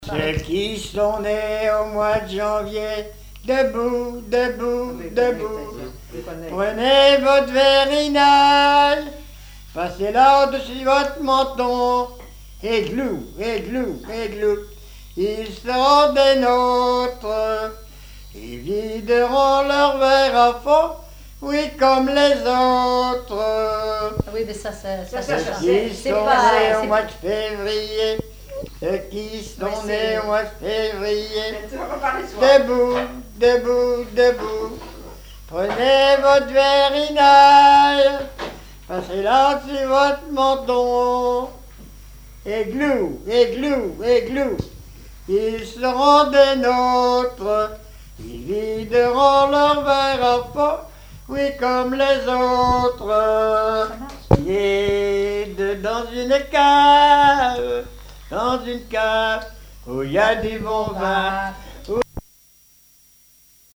Genre énumérative
recueil de chansons populaires
Pièce musicale inédite